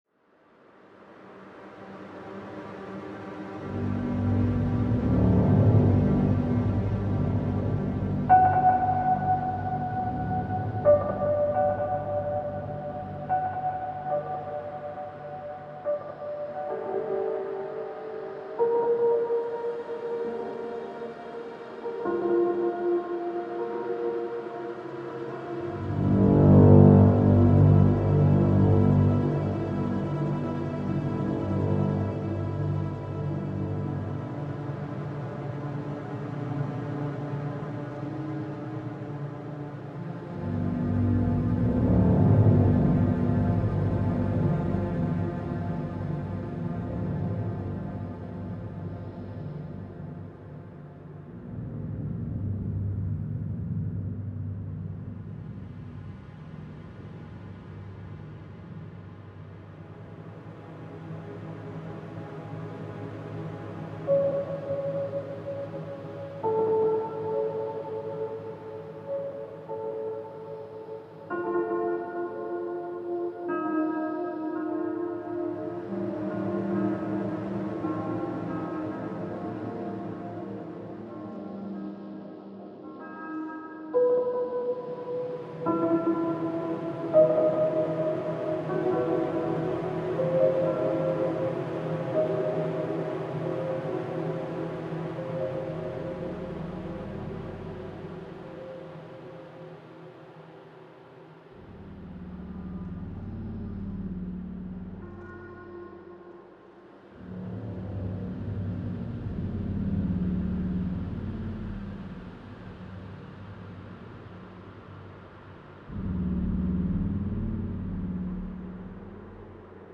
dark atmosphere